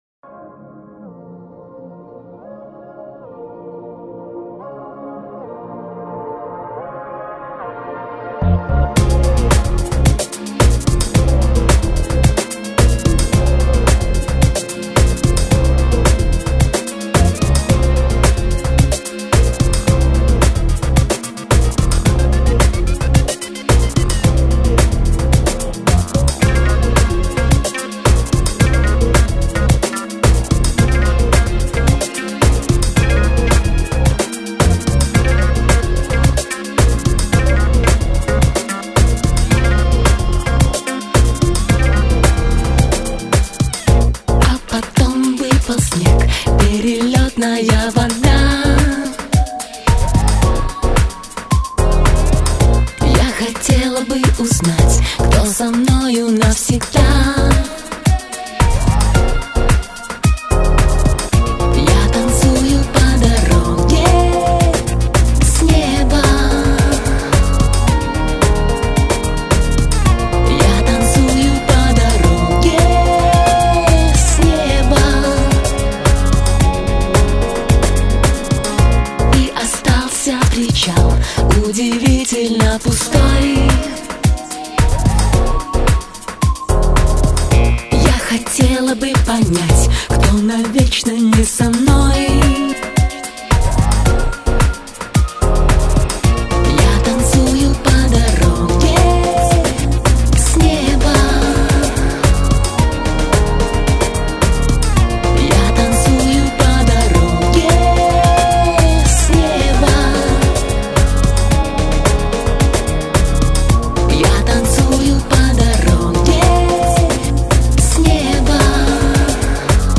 Танцевальная, креативная музыка
• Жанр: Танцевальная